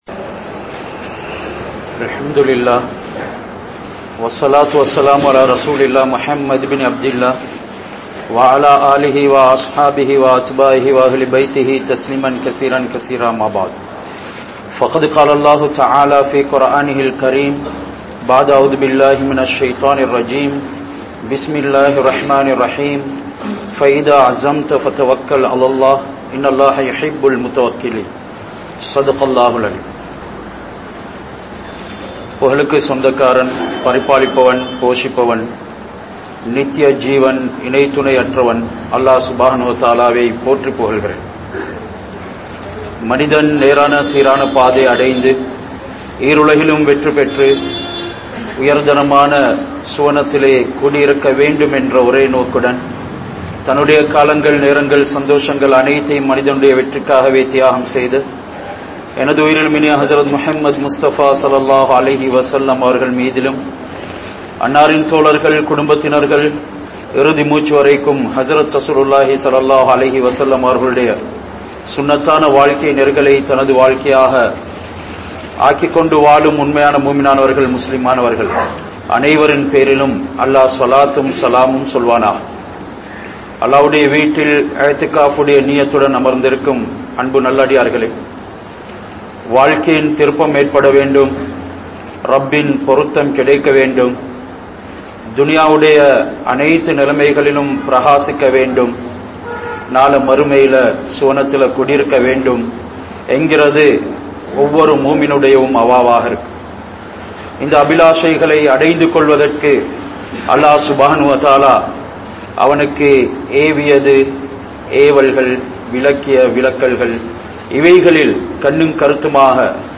Avasarappadaatheerhal (அவசரப்படாதீர்கள்) | Audio Bayans | All Ceylon Muslim Youth Community | Addalaichenai